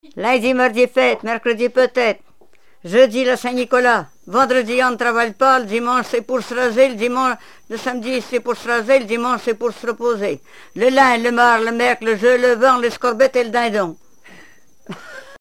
enfantine : comptine
Répertoire de chansons traditionnelles et populaires